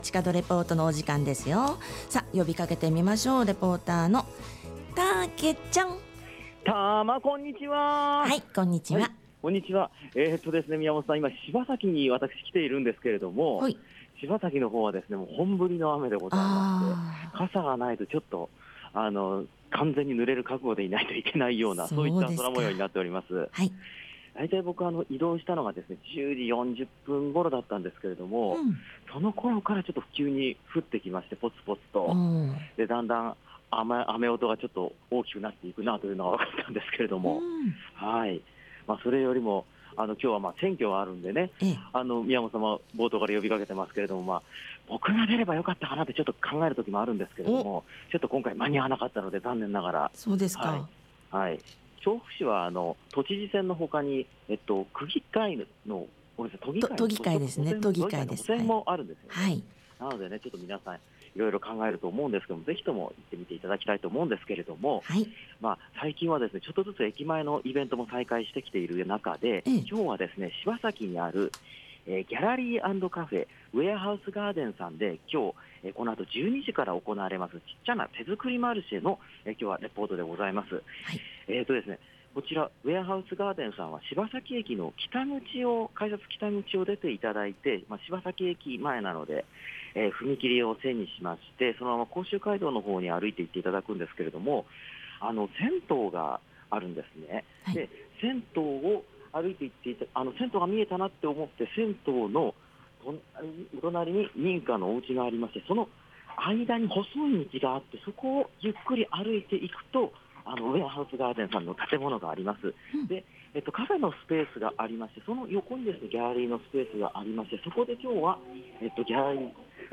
今週の街角レポートは、神金自転車商会さんにお邪魔して、6月いっぱい行われていたキッチンカーの販売についてのレポートです！